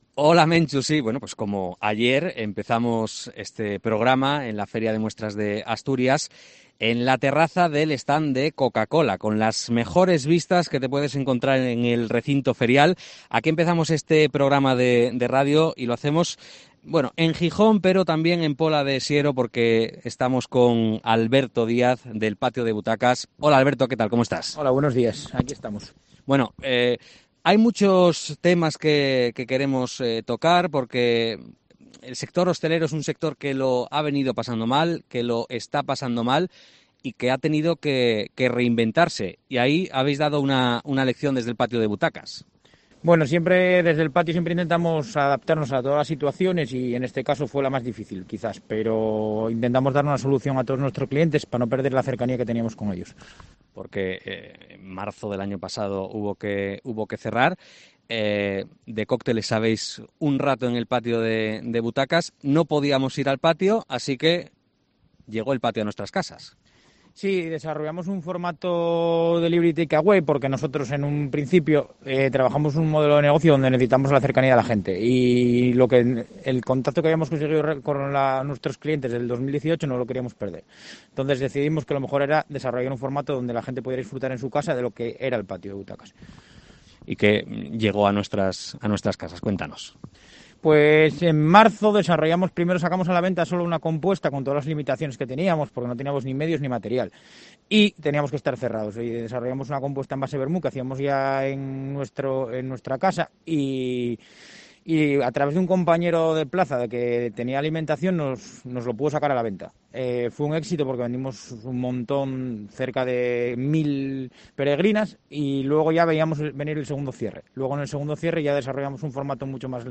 Entrevista
en el stand de Coca-Cola en la FIDMA